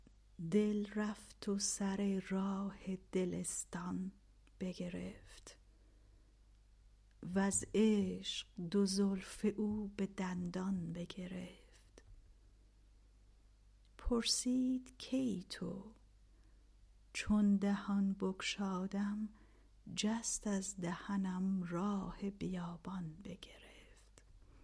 خوانش